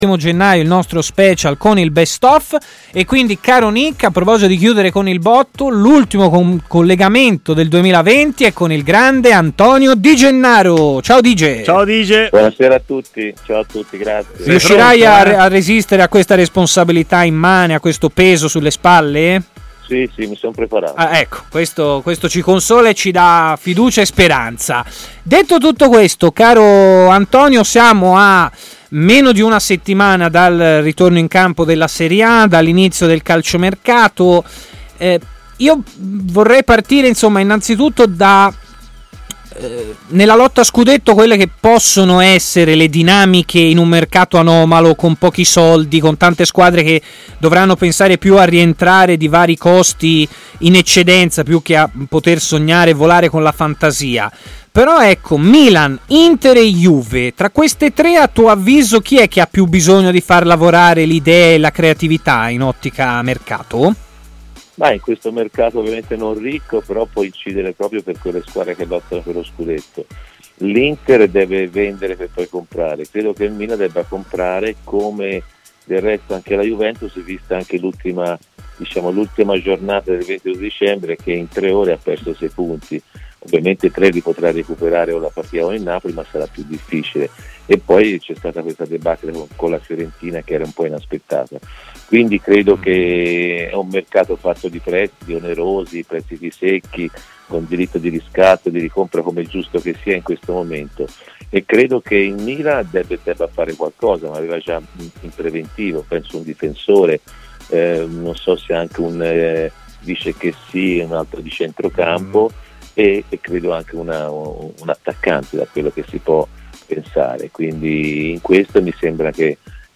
L'ex centrocampista Antonio Di Gennaro ha parlato in diretta a Stadio Aperto